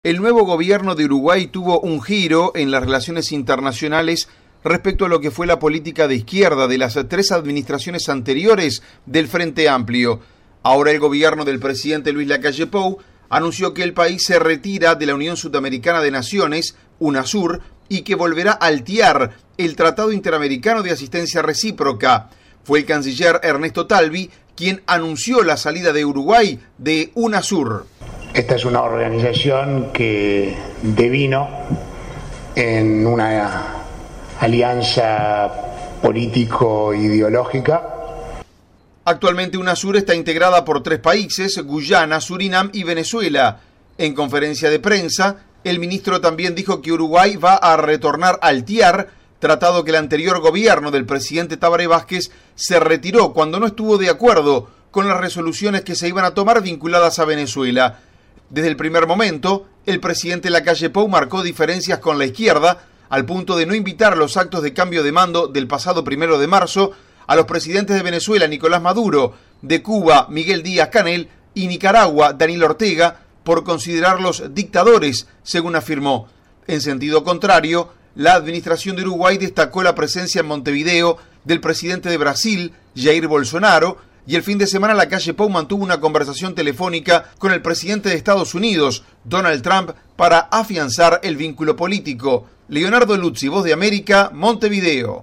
VOA: Informe de Uruguay